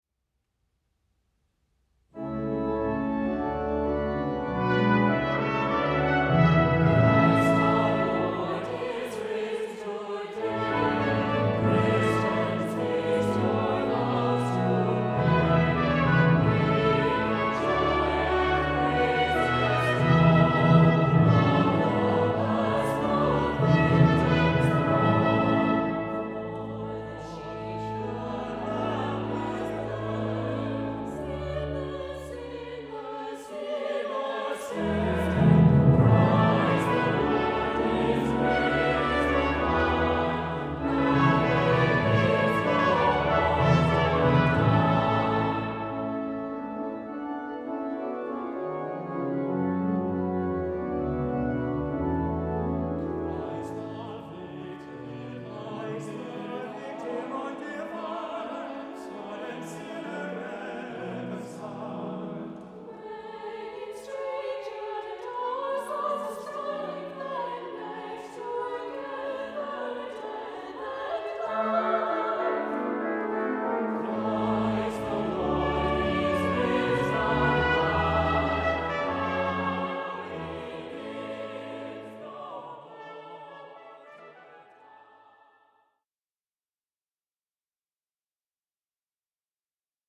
• Music Type: Choral
• Voicing: SATB
• Accompaniment: Congregation, Organ, Percussion, Trumpet
A festive Easter anthem with trumpet and tympani
The work ends in triumphal style with a trumpet descant.